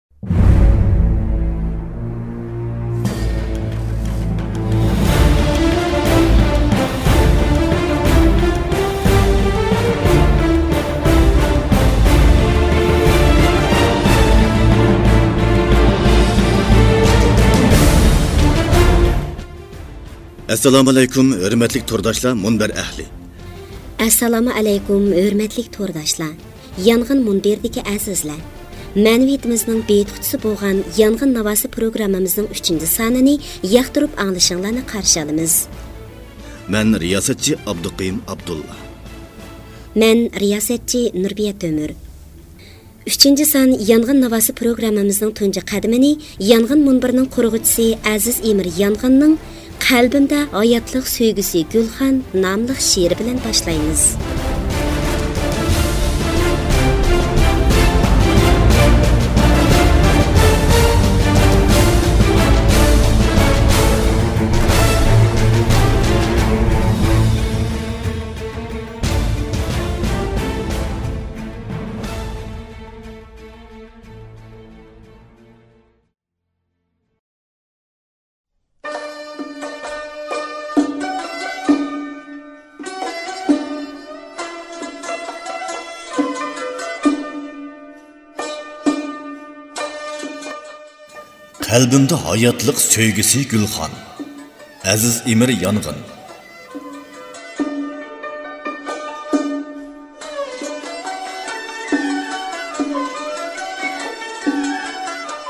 دېكتورلارنىڭ ئاۋازىمۇ بەك ۋەزىنلىك، يېقىملىقكەن.